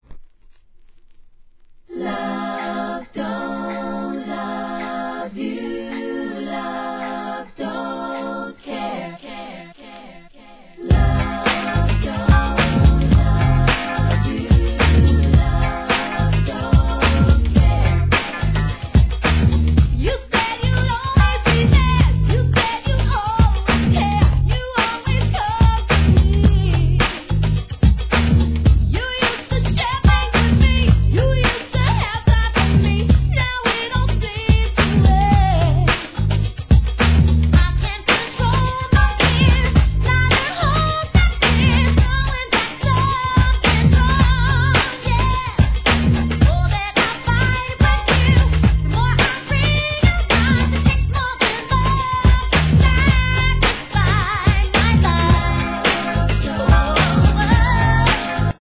HIP HOP/R&B
NEW JACK SWING!!